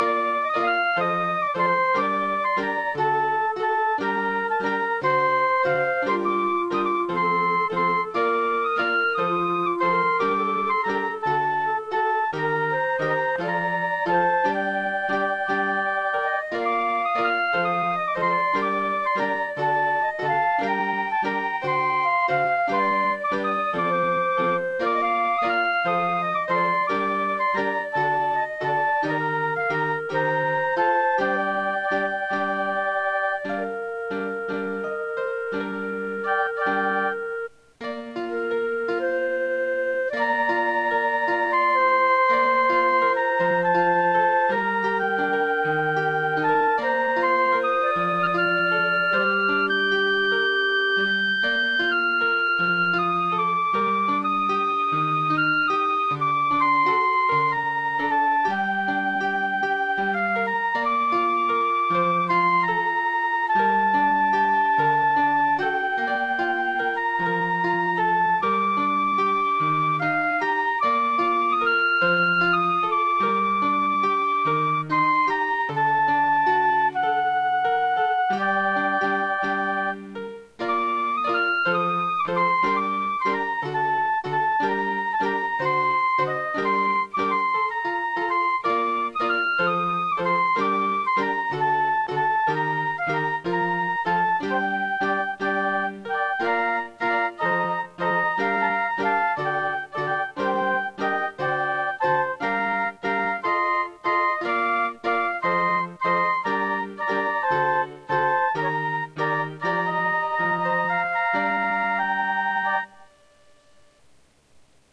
I didn't sit down and piece all the notes together by hand. Each track recordedlive with me playing the notes.